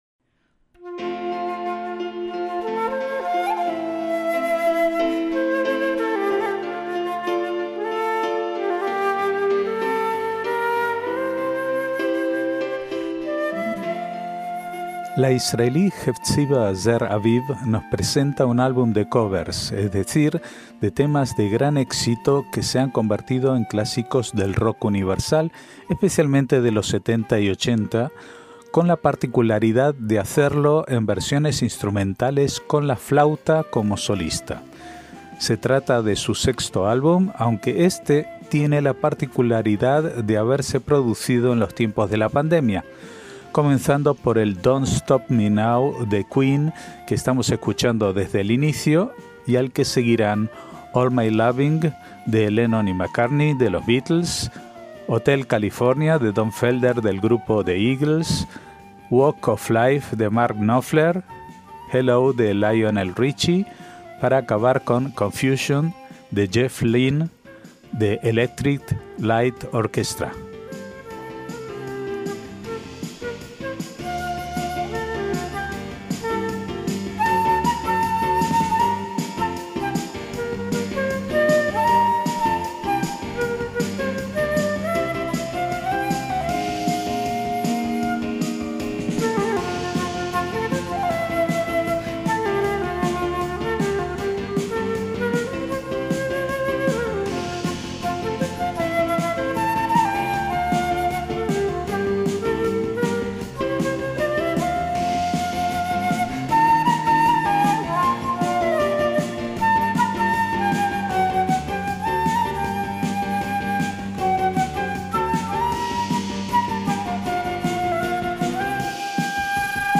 MÚSICA ISRAELÍ
clásicos del rock universal
versiones instrumentales con la flauta como solista